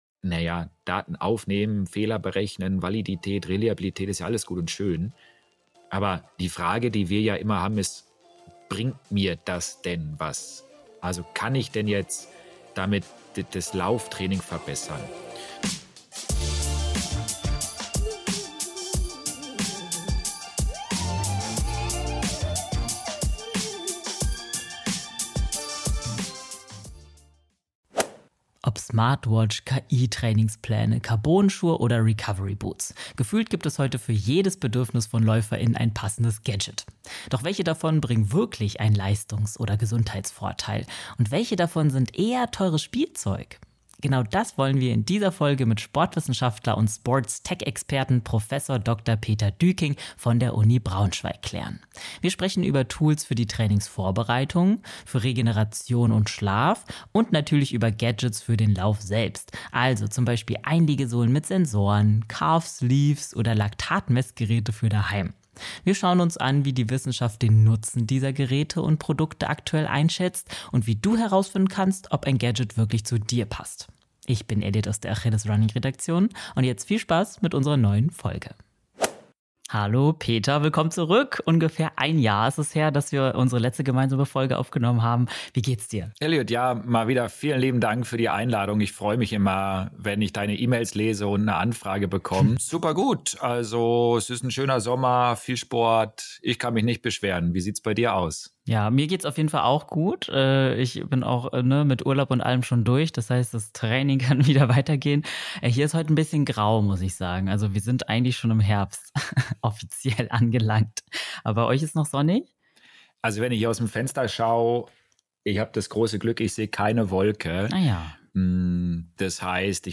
Smart-Tools, Calf Sleeves, Recovery-Boots & Co. - in dieser Folge sprechen wir mit Sportwissenschaftler